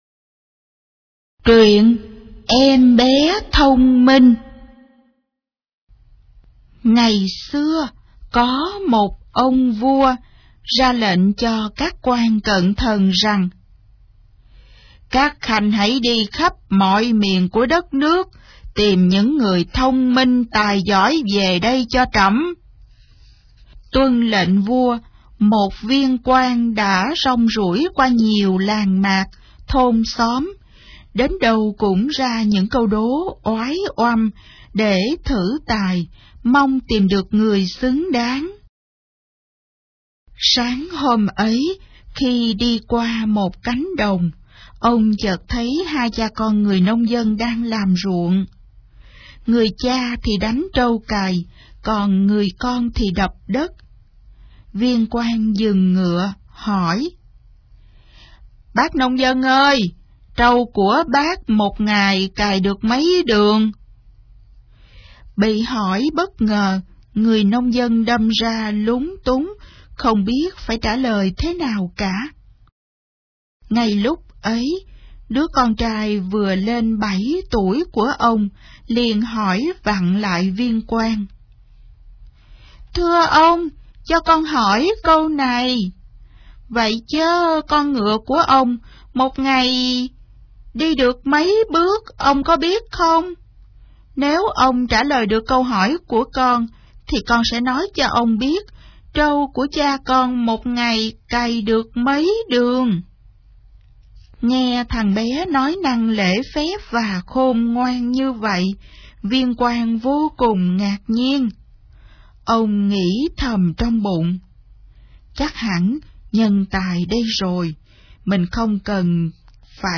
Sách nói | Cây Đa Biết Nói